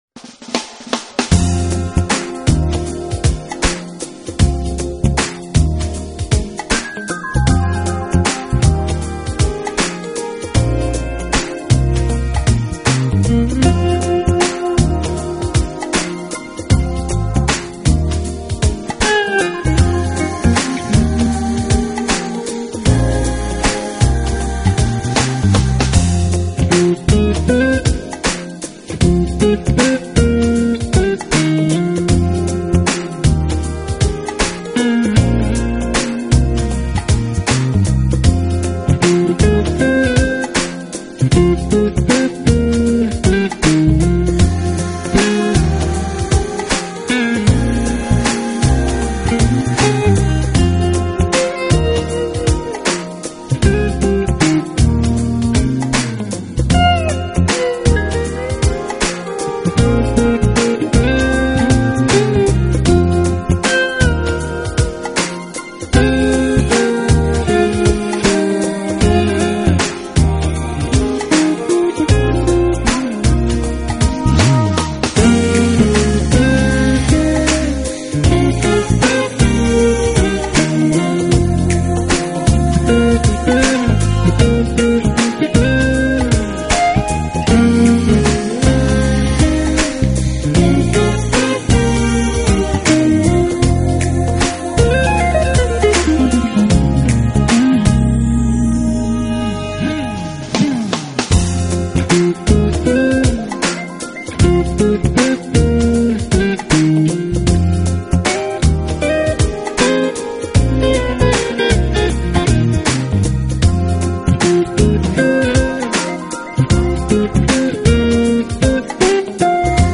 音乐类型：Jazz
tenor  saxophone
trumpet,  flugelhorn
drums
Smooth Jazz